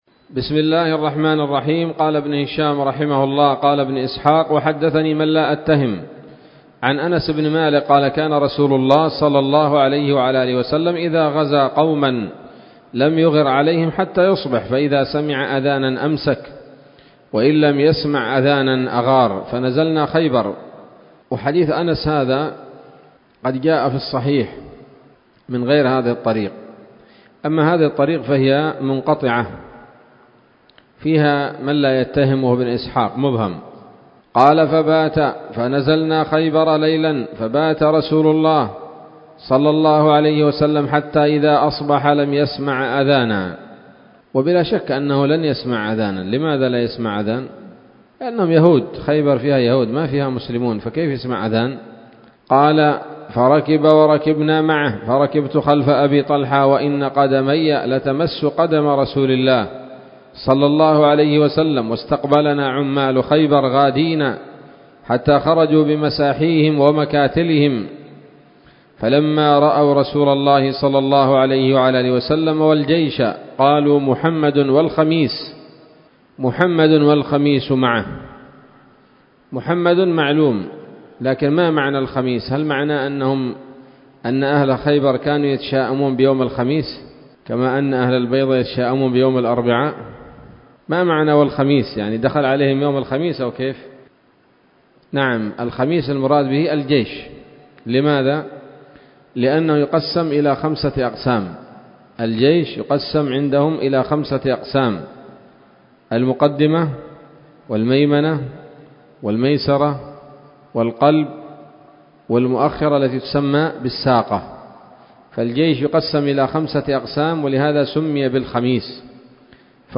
الدرس الثامن والثلاثون بعد المائتين من التعليق على كتاب السيرة النبوية لابن هشام